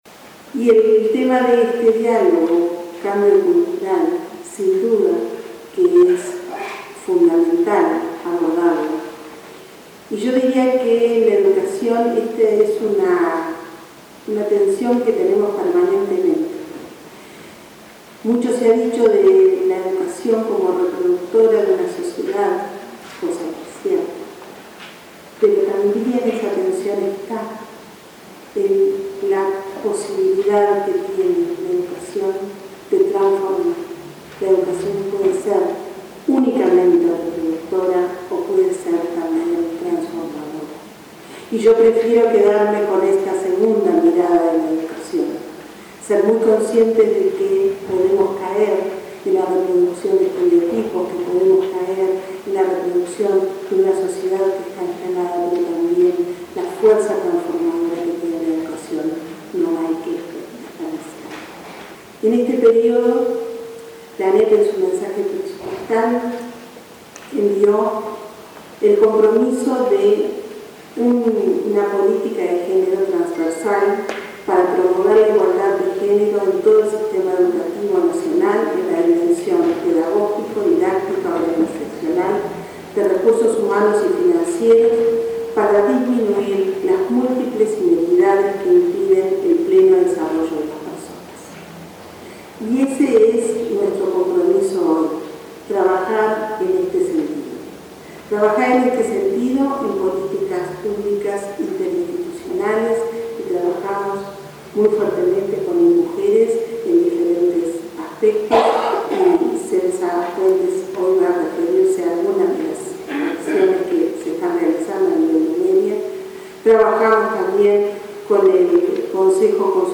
Cambio cultural, derechos humanos y violencia basada en género fueron eje del seminario realizado este viernes en el teatro Solís. En ese marco, la consejera del Codicen, Laura Motta, recordó que la ANEP trabaja en una política de género transversal para promover la igualdad de género en todo el sistema educativo. Motta enumeró el trabajo que se realiza con diferentes organismos del Estado para alcanzar dicho objetivo.